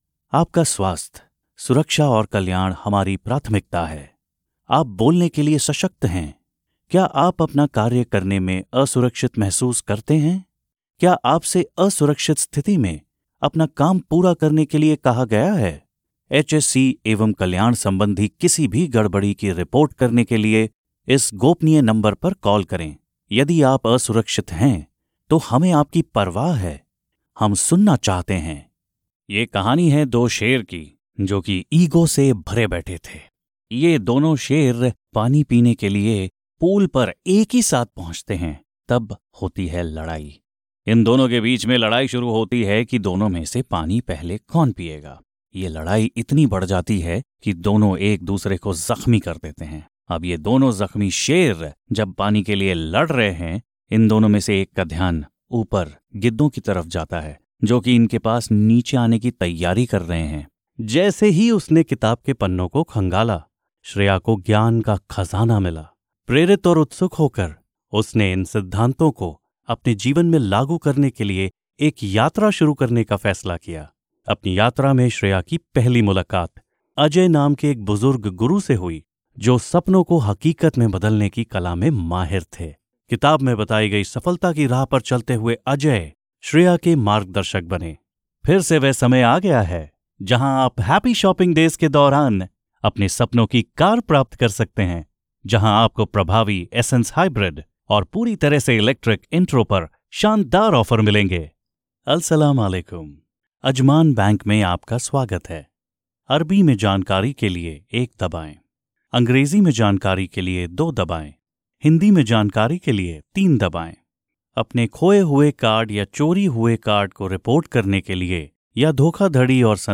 Male
Approachable, Bright, Cool, Corporate, Friendly, Natural, Smooth, Soft, Warm
English Indian Accent (Native)
Microphone: Electro Voice RE20